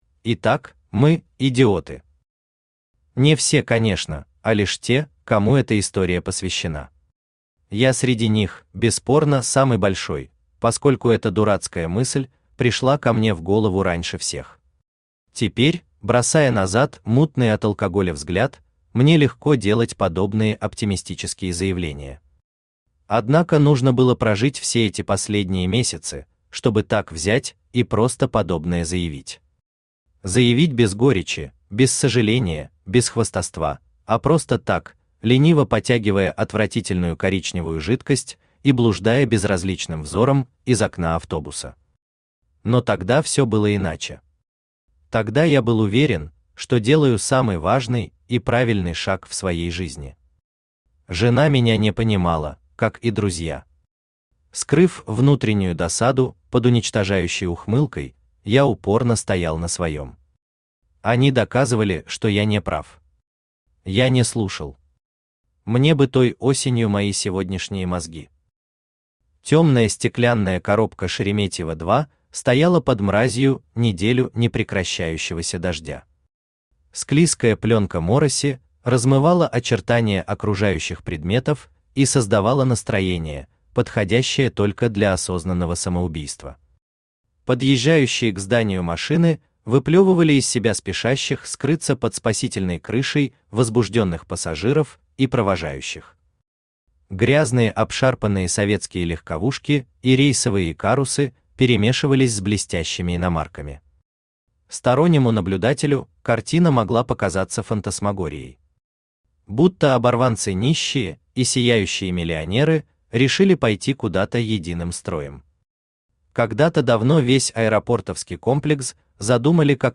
Аудиокнига Мы идиоты | Библиотека аудиокниг
Aудиокнига Мы идиоты Автор Василий Чешихин Читает аудиокнигу Авточтец ЛитРес.